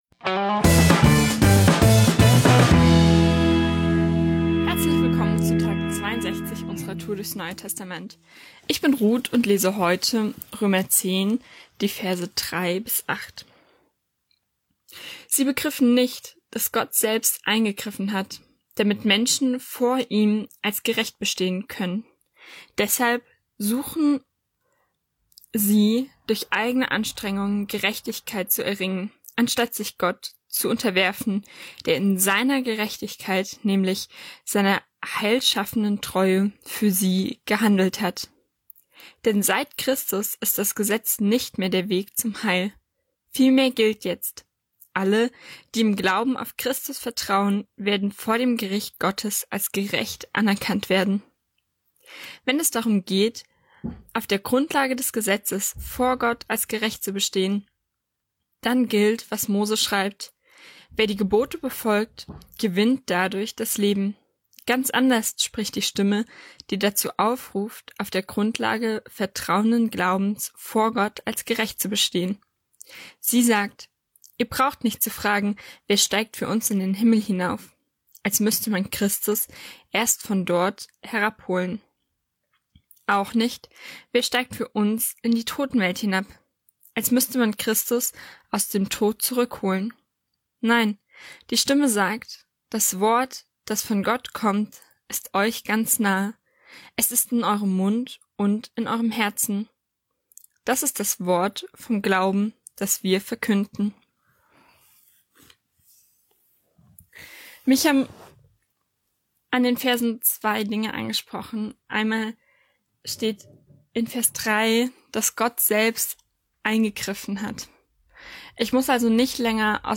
38 Menschen aus unserer Kirche lesen kleine Abschnitte je eines Kapitels aus den Evangelien, der Apostelgeschichte und den Briefen des Neuen Testaments.